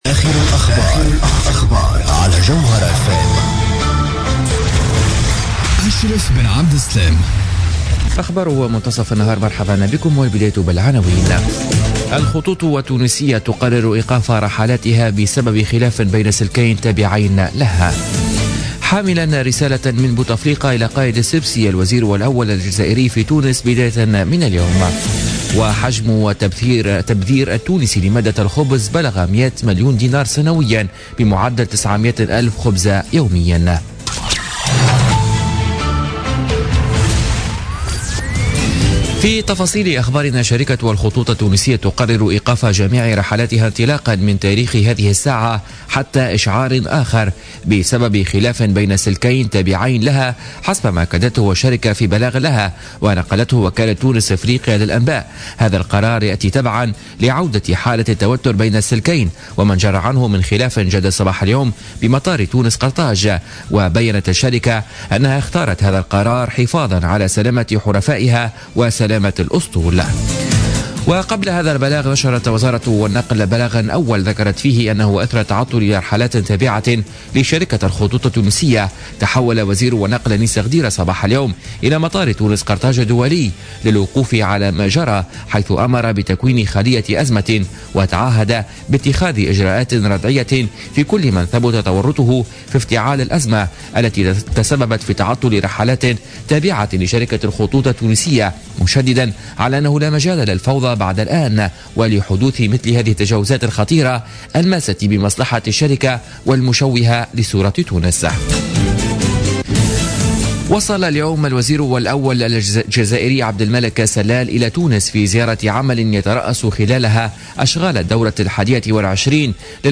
نشرة أخبار منتصف النهار ليوم الخميس 9 مارس 2017